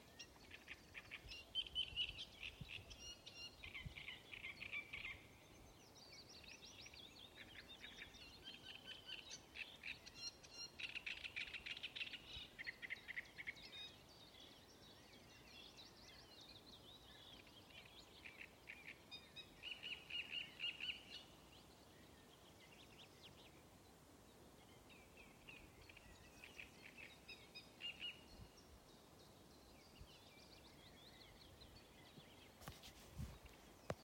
Niedru strazds, Acrocephalus arundinaceus
StatussDzied ligzdošanai piemērotā biotopā (D)
PiezīmesBalss no otra krasta uzpludinātajam karjeram